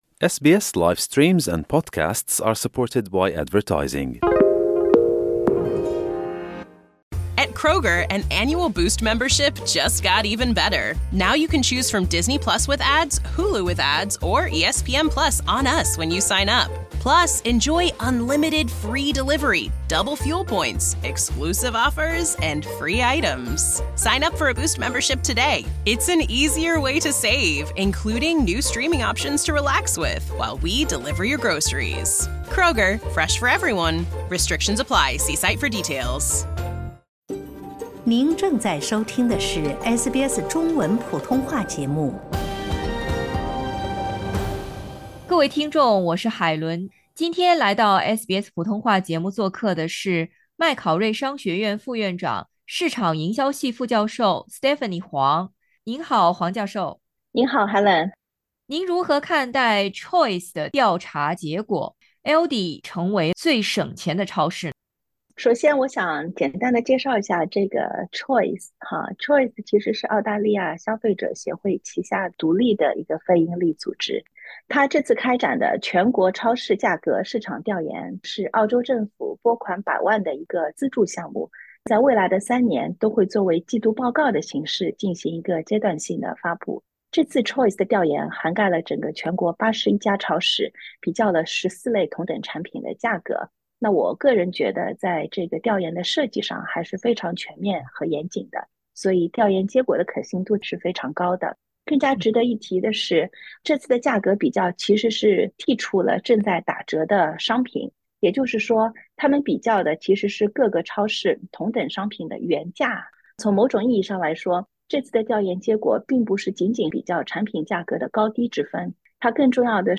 消费者团体Choice的一份研究报告为您揭开谜底。请点击音频，收听采访。